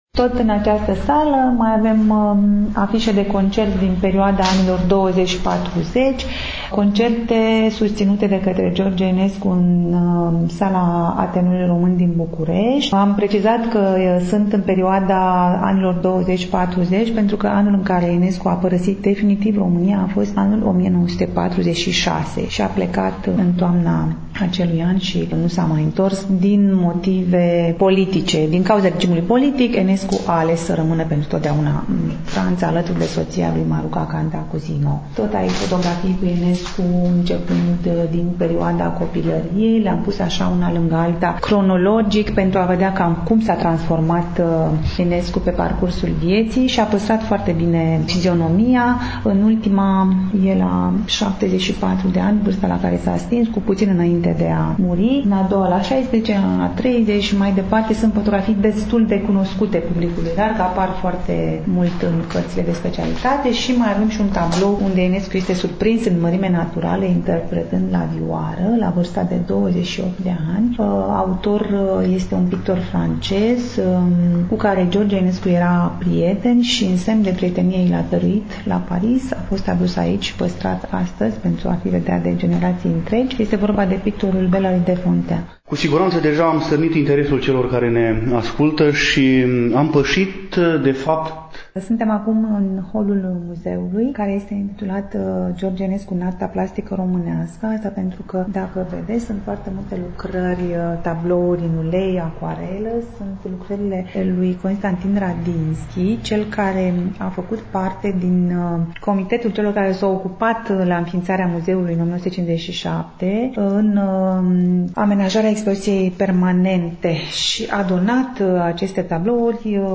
Reamintim că facem popas, astăzi, în incinta Muzeului Memorial „George Enescu” din Dorohoi, punct muzeal situat pe strada George Enescu, nr. 81.